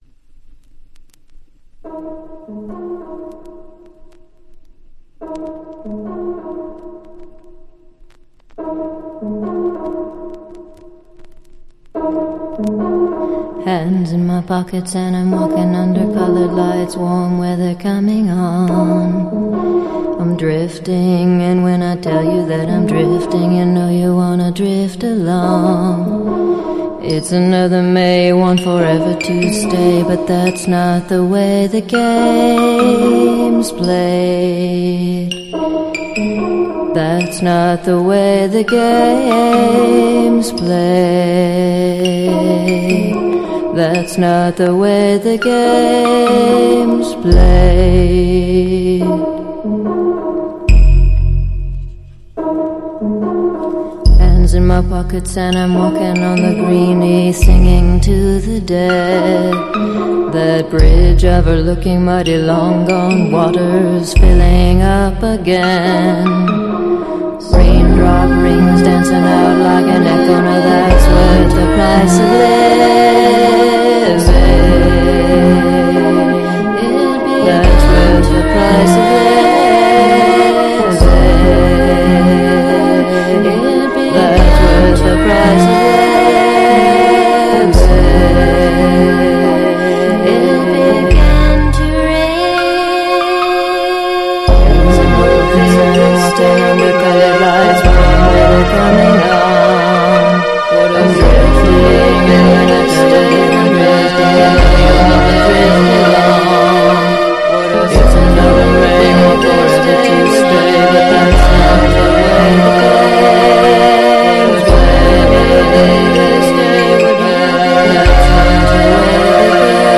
House / Techno
(Vocal Version)